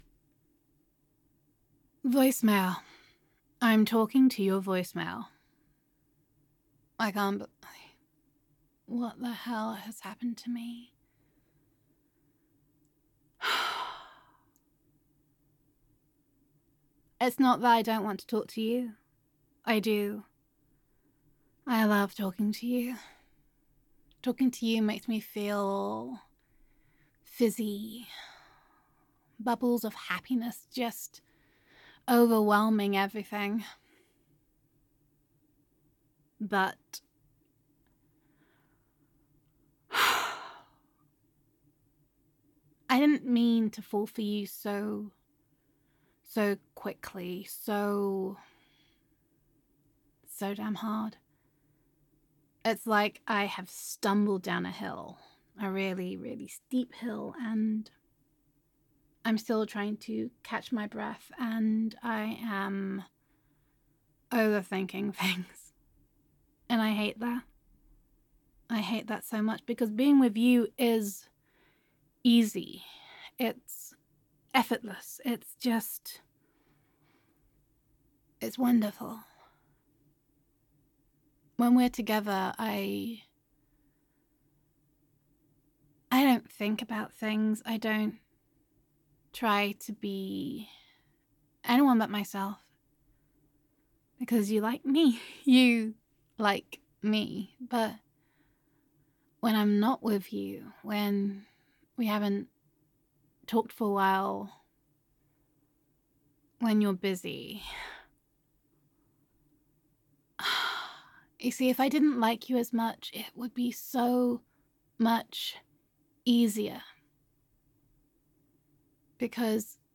[F4A] Overthinking It [Adoration][Stupid in Love with You][Realisation][Wow][Girlfriend Roleplay][Gender Neutral][Accidental Love Confession Voicemail]